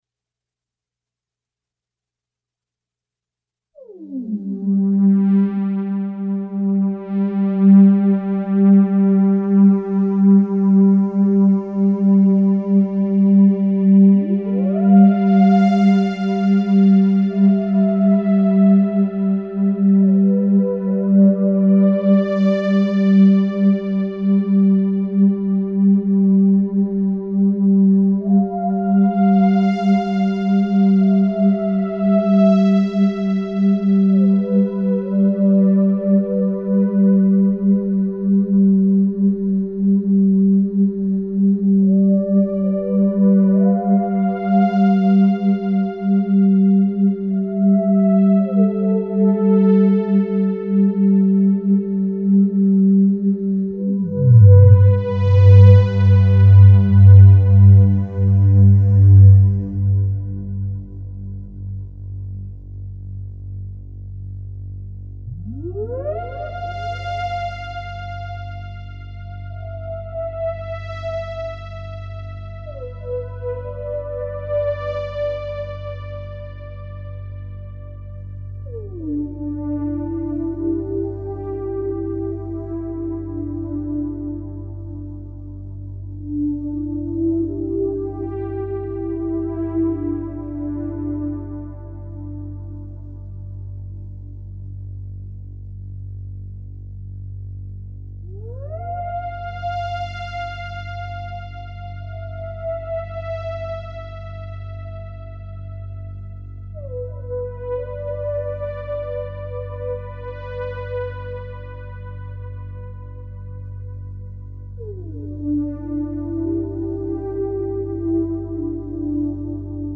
It’s the last drone piece I wanted to post that seemed to round out
It flows easier now. Almost through composed.